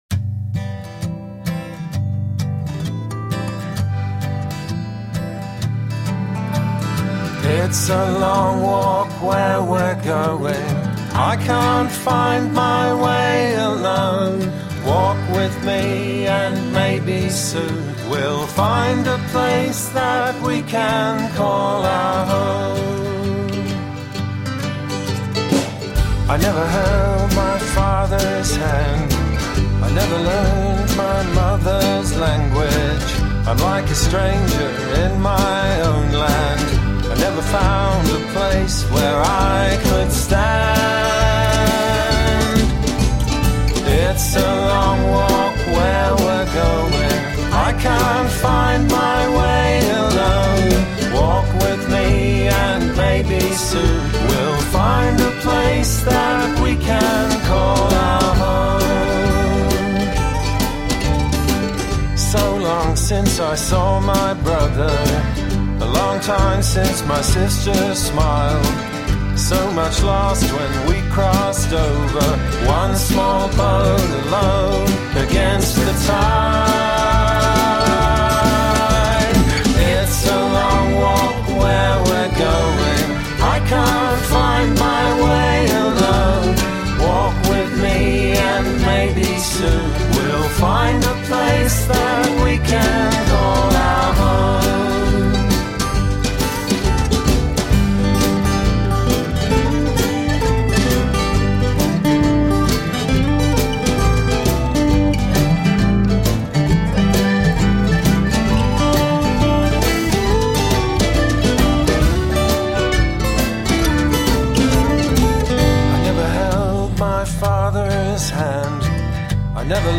Contemporary folk.
Tagged as: Alt Rock, Folk-Rock, Folk